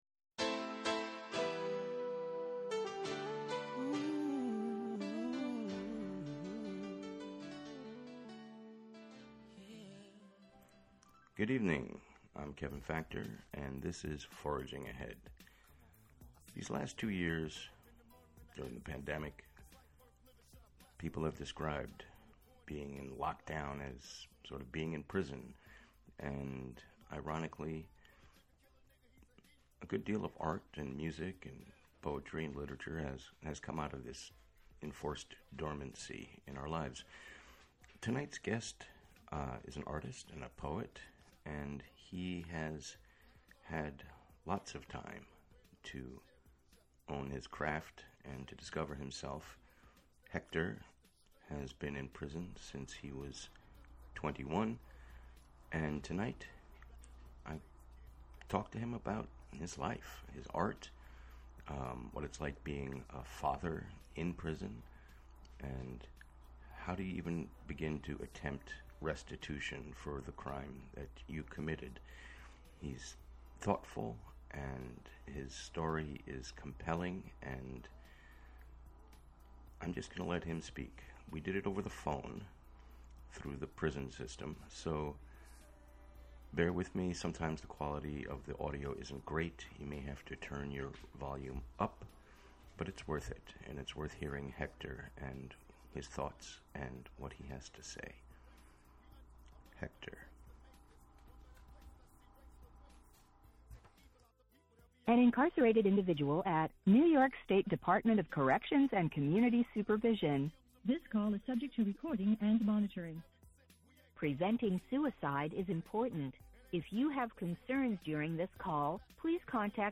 featuring music and interviews
broadcast live from WGXC's Hudson studio